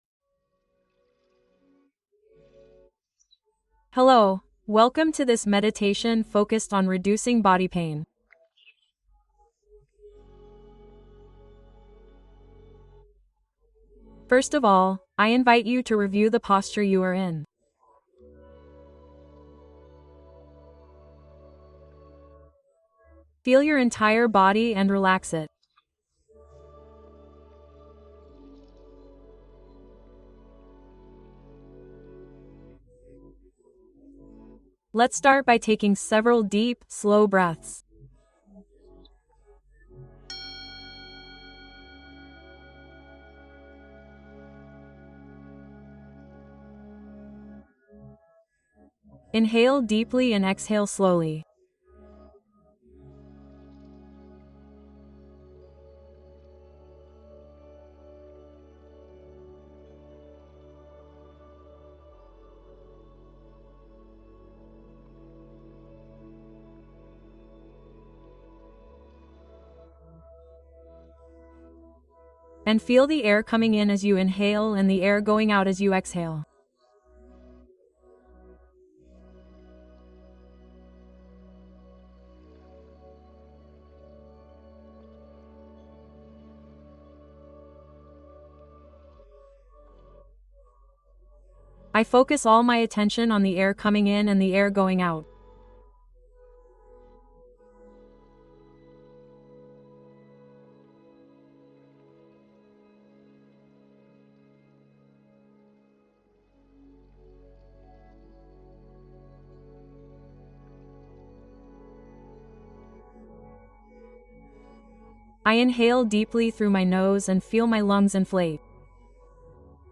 Descansar y acompañar el cuerpo: meditación nocturna consciente